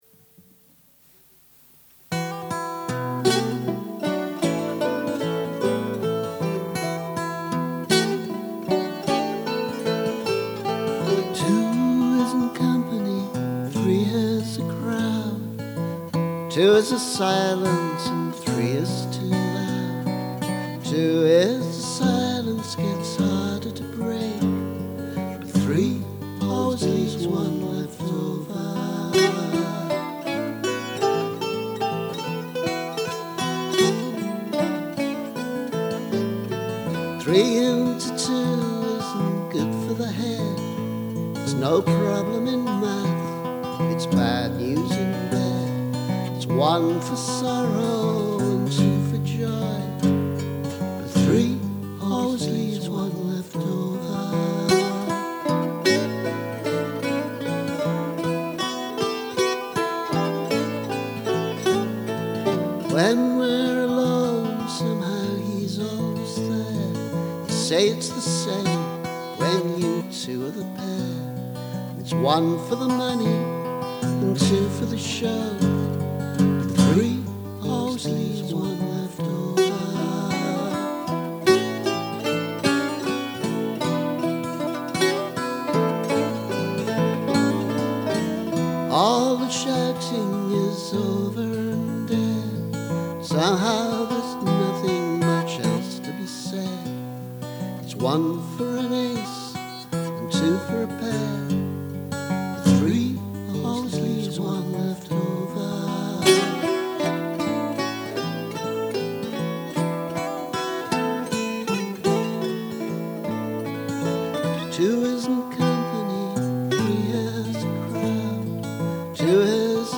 Version with added bouzouki: